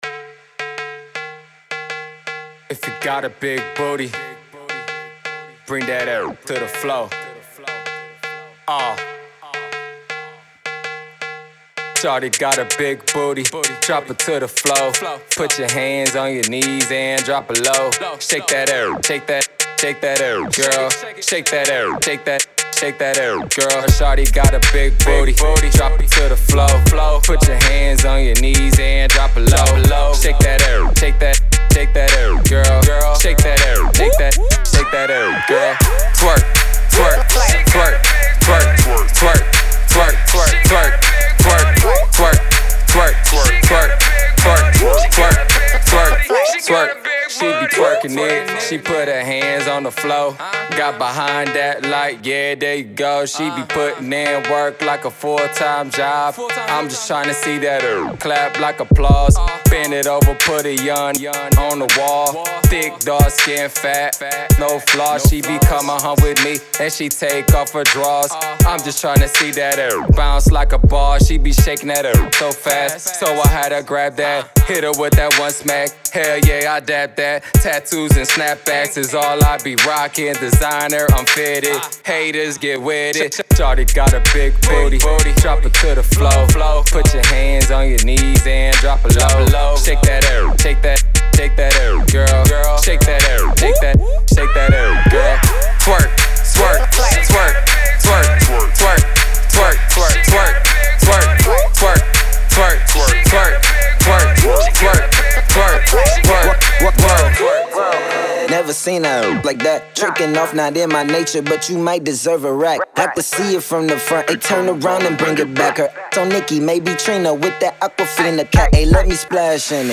Summer Banger